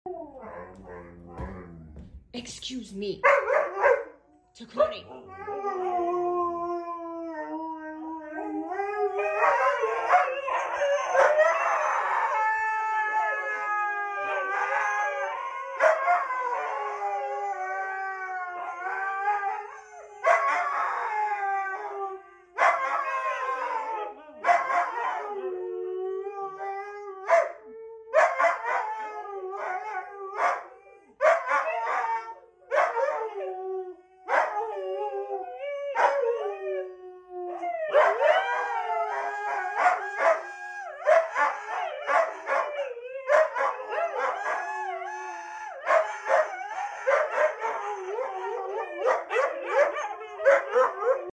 Life With 3 Huskies 😅 Sound Effects Free Download